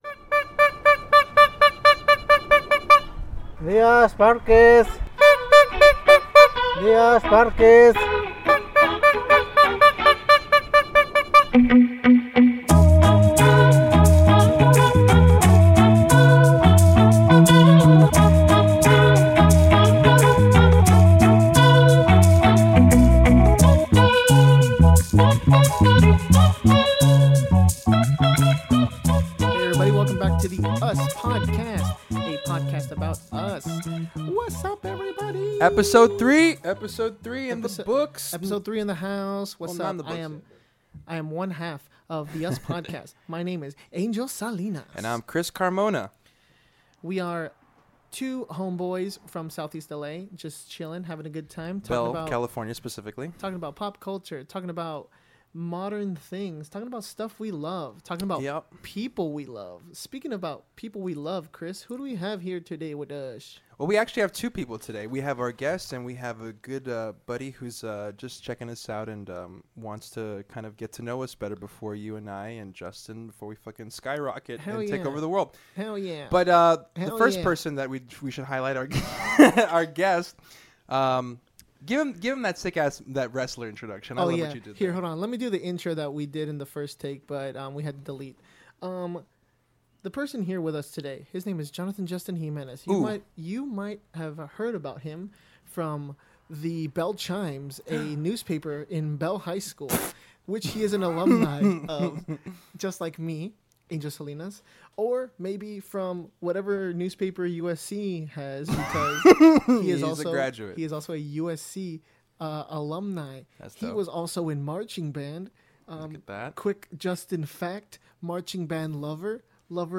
Headliner Embed Embed code See more options Share Facebook X Subscribe Interview with our homie